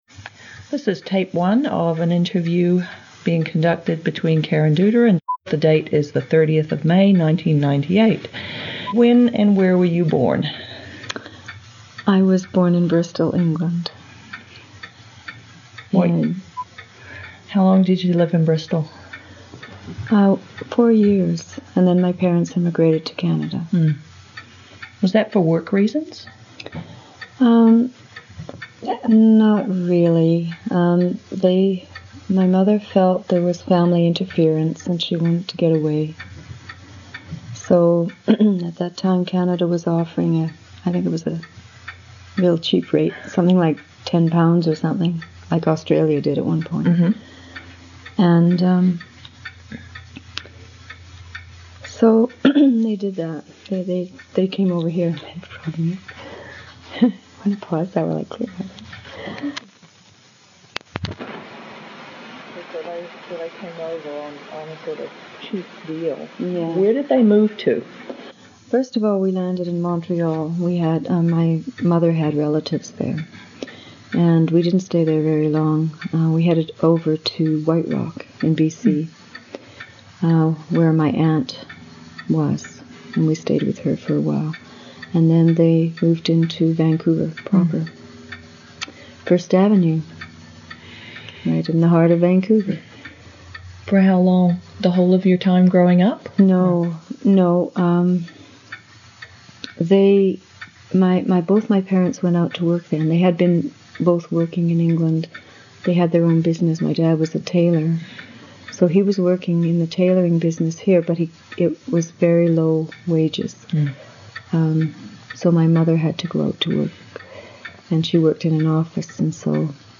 Lesbian and Bisexual Women in English Canada audio history collection